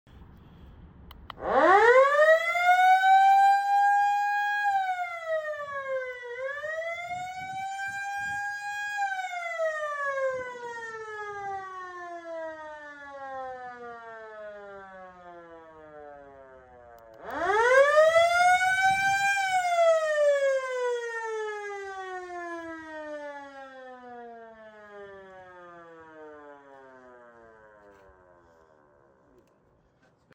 Remote activated, mini siren going sound effects free download